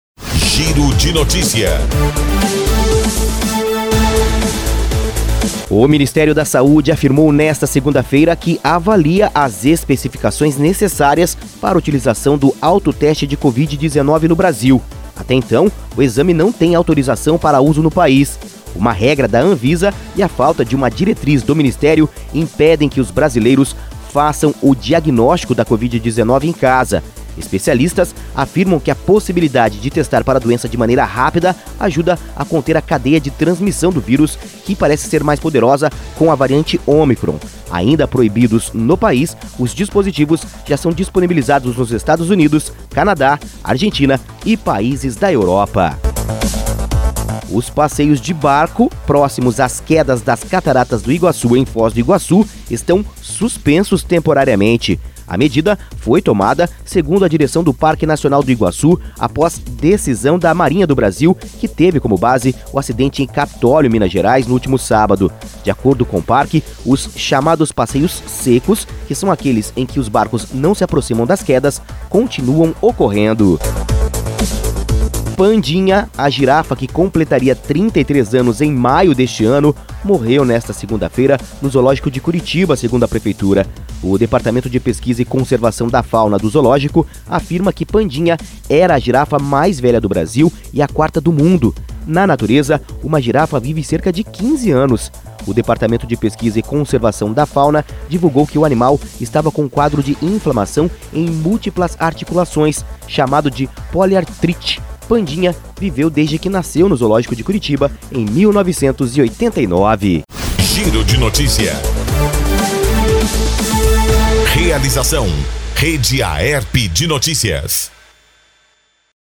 Giro de Notícias – Manhã